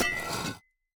Minecraft Version Minecraft Version snapshot Latest Release | Latest Snapshot snapshot / assets / minecraft / sounds / item / axe / scrape1.ogg Compare With Compare With Latest Release | Latest Snapshot
scrape1.ogg